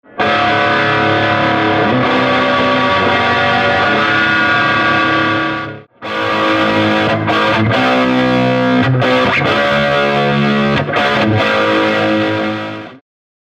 From warm blues overdrive to heavy distortion, this amp delivers rich, full tube tone.